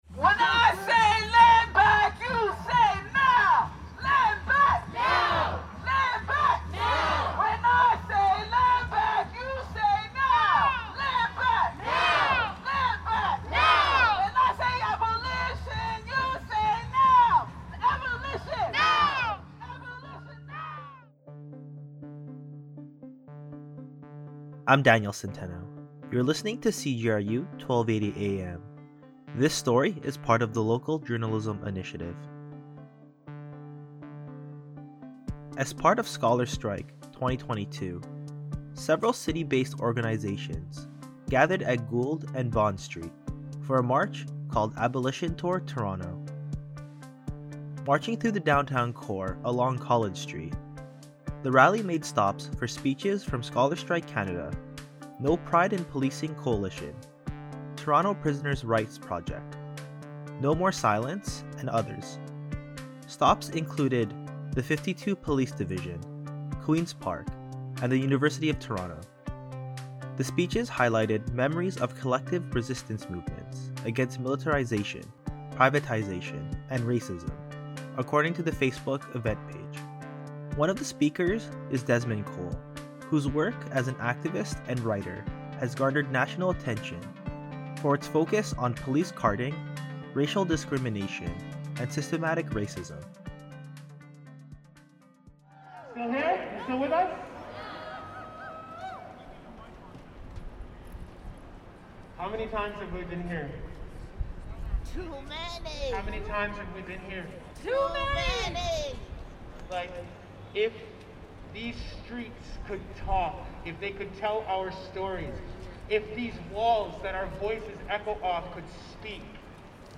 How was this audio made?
The rally made stops for speeches from Scholar Strike Canada, No Pride in Policing Coalition, Toronto Prisoners Rights Project, No More Silence and others.